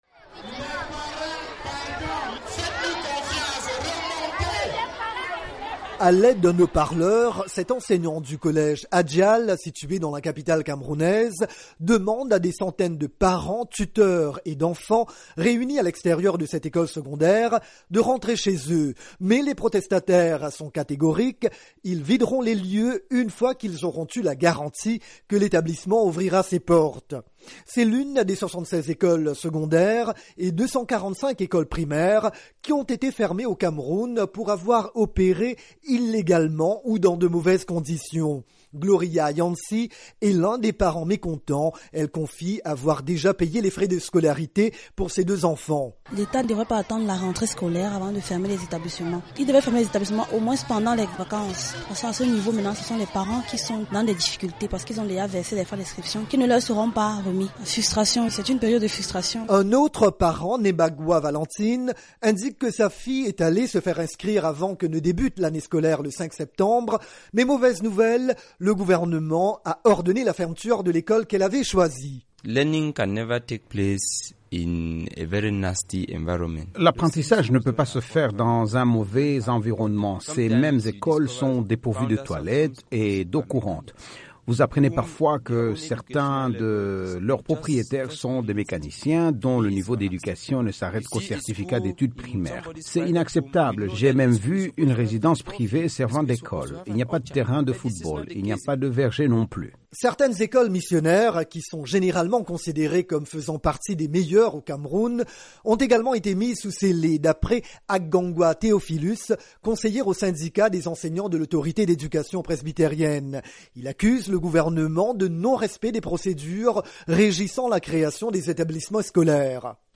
Reportage sur la rentrée scolaire au Cameroun
À l’aide d’un haut-parleur, cet enseignant du collège Adjal situé dans la capitale camerounaise demande à des centaines de parents, tuteurs et d’enfants réunis à l'extérieur de cette école secondaire de rentrer chez eux.